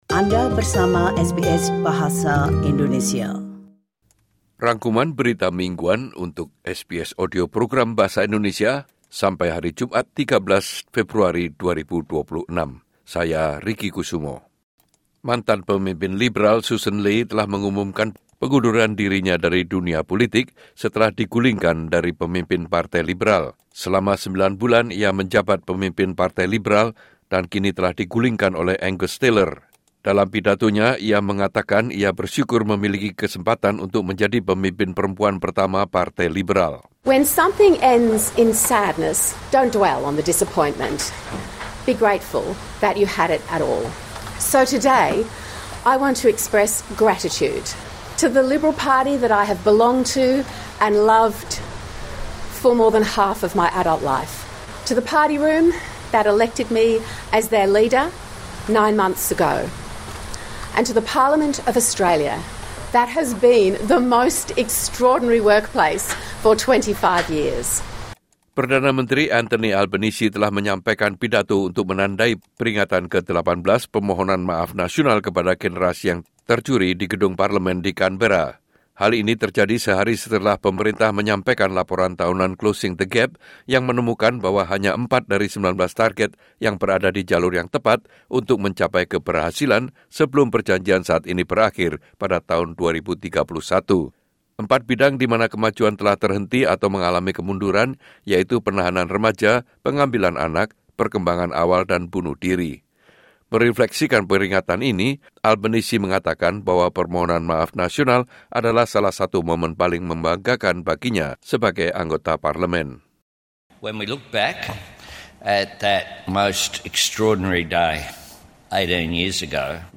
Weekly News Summary SBS Audio Program Bahasa Indonesia - Friday 13 February 2026